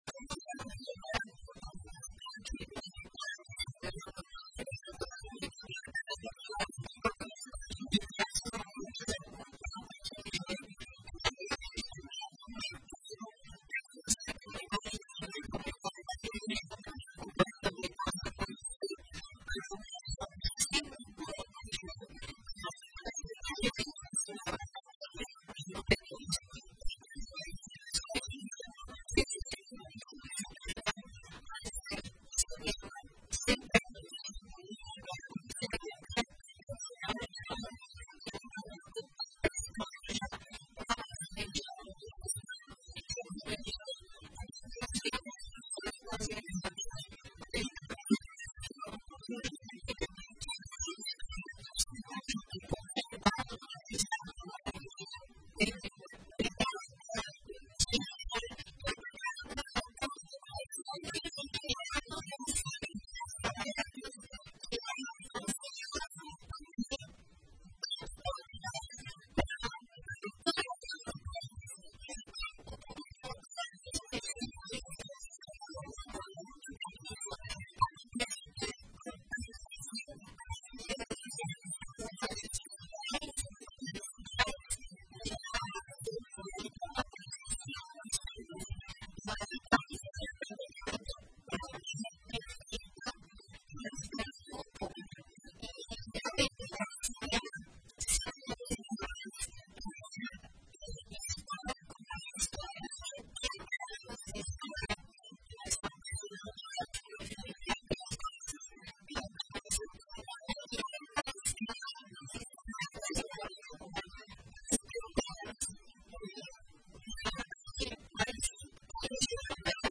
CINE-GLOBO-ENTREVISTA.mp3